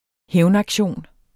Udtale [ ˈhεwn- ]